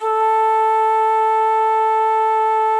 FluteClean2_A2.wav